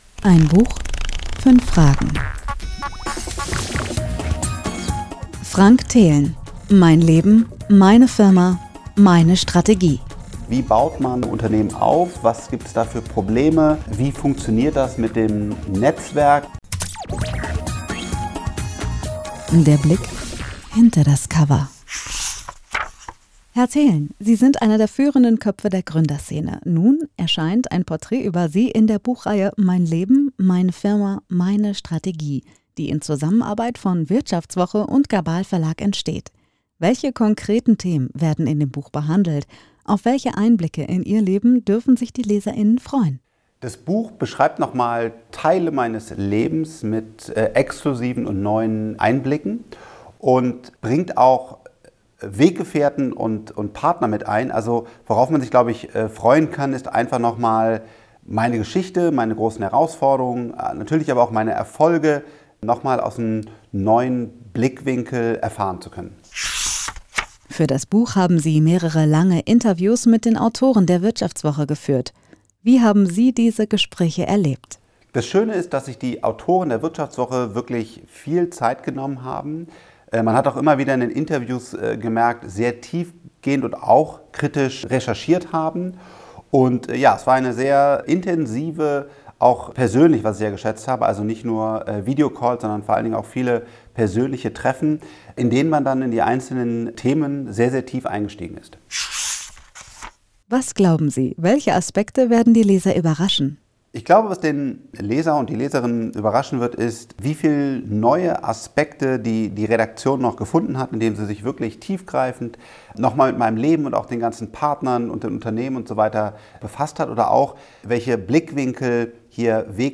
- Interview - GABAL MAGAZIN